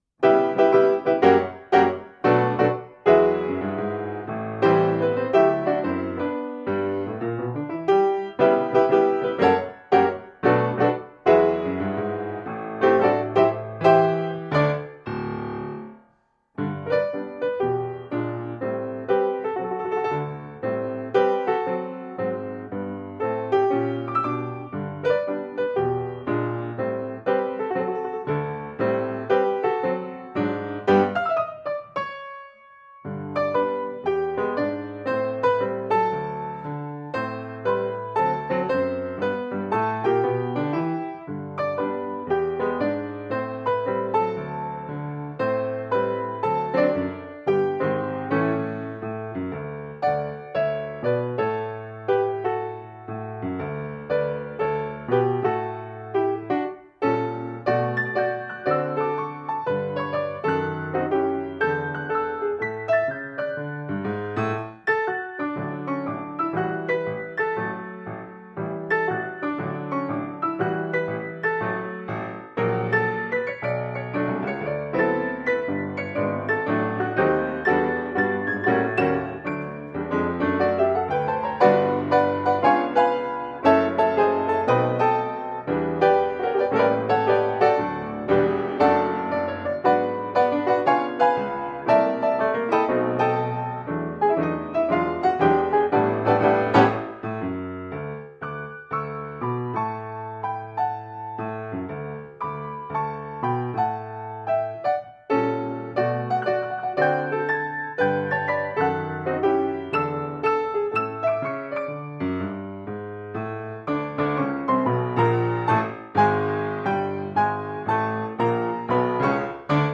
太可爱了！仿佛三只小猪摇摇摆摆地和大灰狼斗智斗勇~